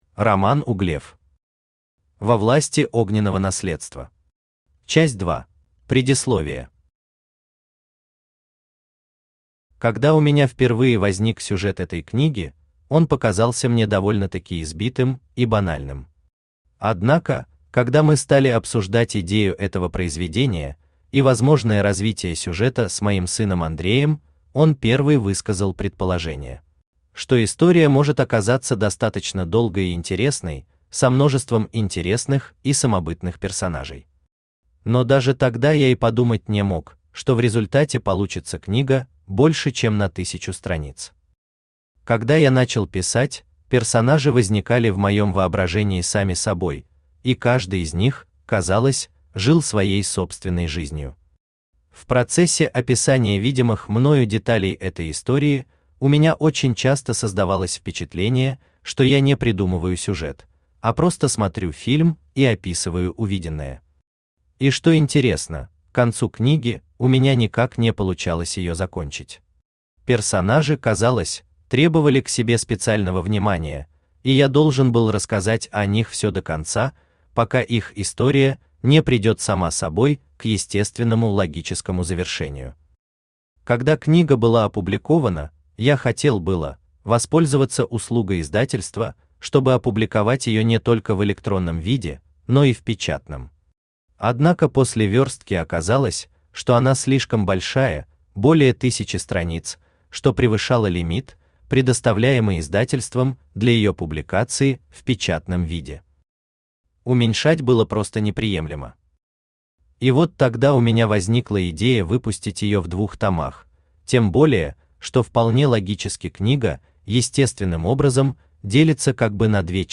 Аудиокнига Во власти огненного наследства. Часть 2 | Библиотека аудиокниг
Часть 2 Автор Роман Романович Углев Читает аудиокнигу Авточтец ЛитРес.